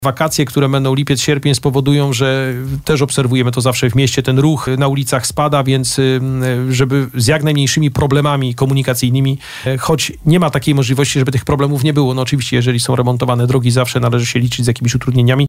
To pytanie zadaliśmy w trakcie rozmowy z zastępcą prezydenta Bielska-Białej.
Najpierw realizowane będą prace podziemne, a później przystąpimy do realizacji nowej warstwy ul. Piastowskiej – objaśniał na naszej antenie wiceprezydent miasta, Adam Ruśniak.